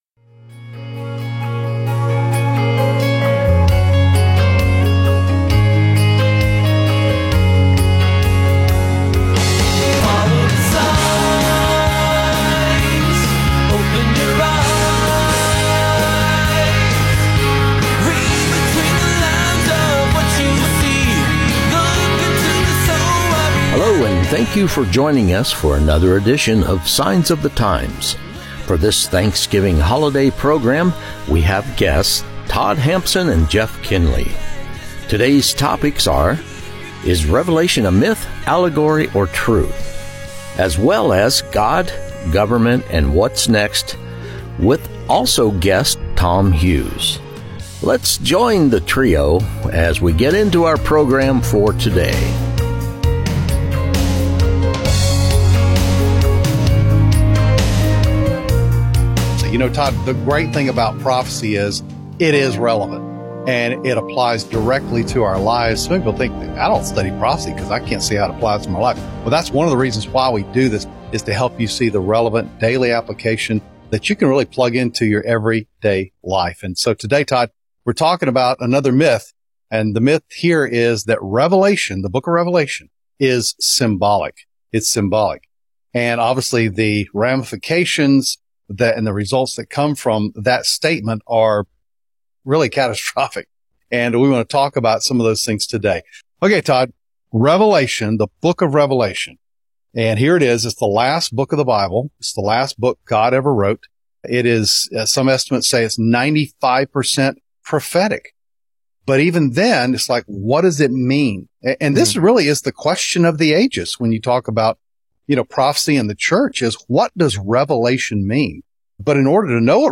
This podcast is primarily focused on news events that support Bible prophecy. Signs Of The Times originates as a weekly radio program provided through The Way Media and Calvary Knoxville, a non-denominational Christian Church.